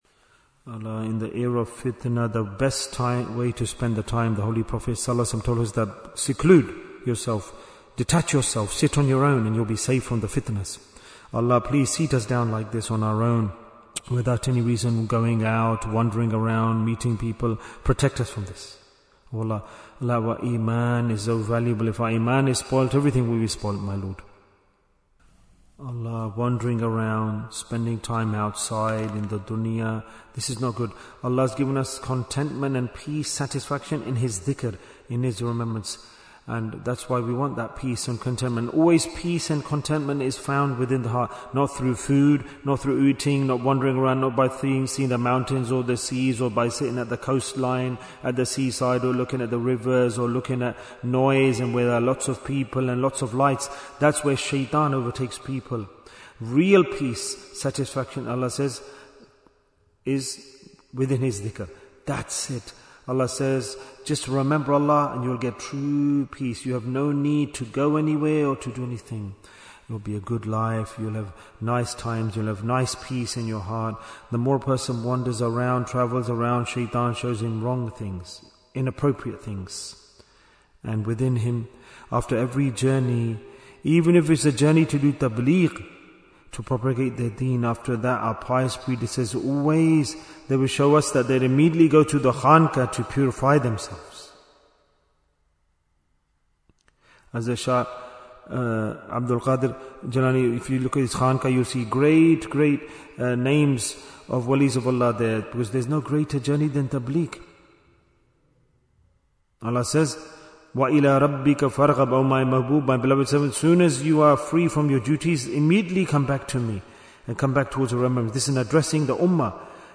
- Part 10 Bayan, 33 minutes22nd January, 2026